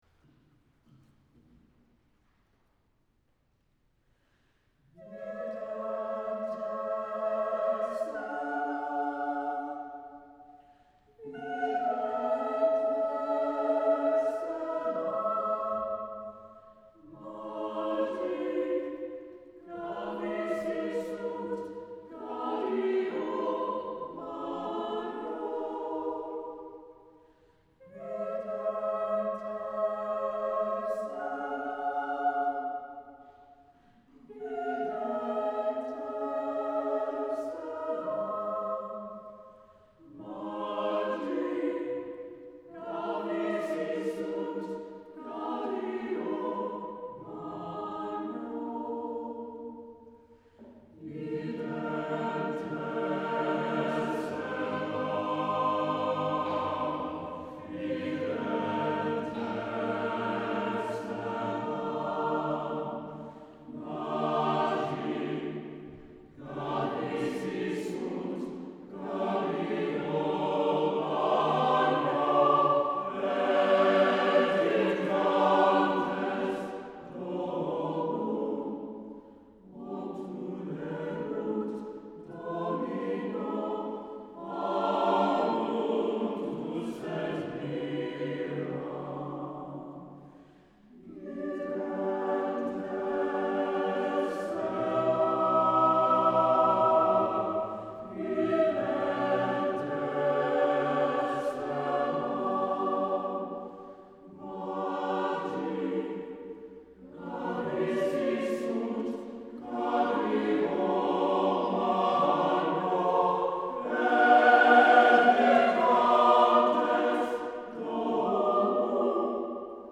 Santa Barbara City College Choir Concert, Fall 2007